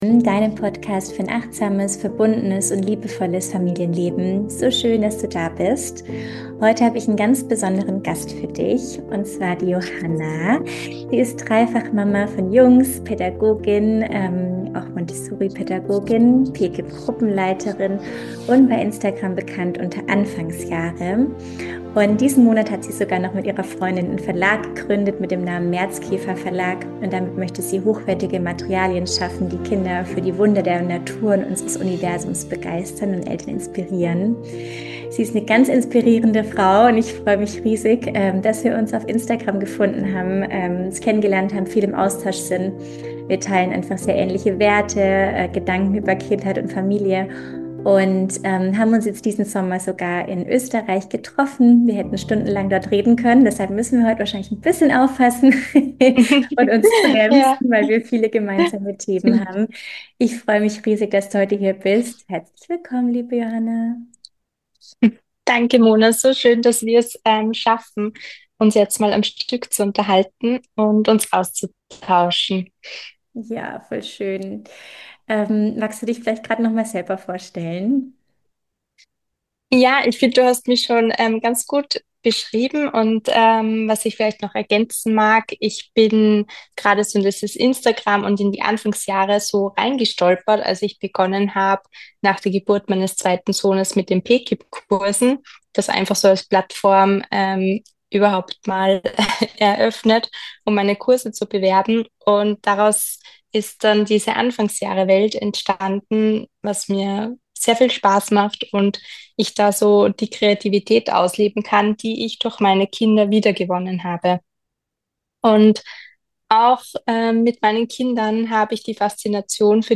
102 Montessori verstehen -jenseits des Trends - Interview